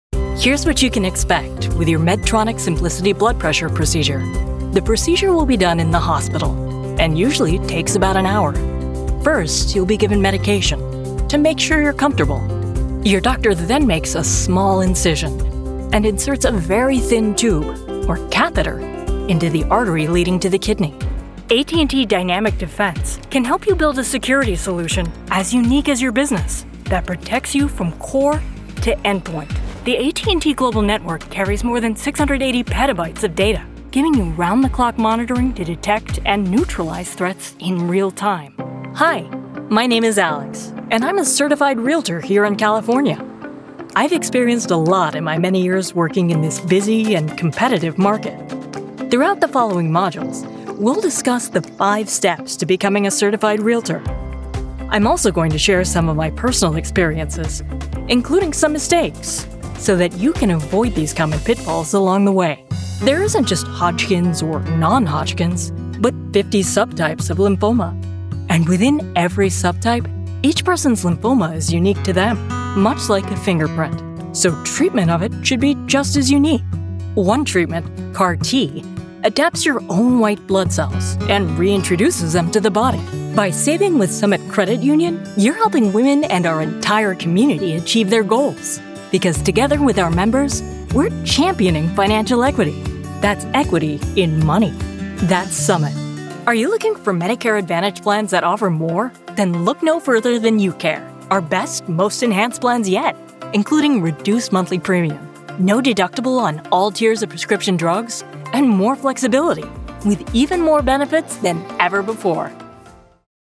Voice Actress
Med/Tech Narration
Microphones: Neumann u87 ai, Sennheiser MKH416
Recording Space: Dedicated acoustically Treated booth Space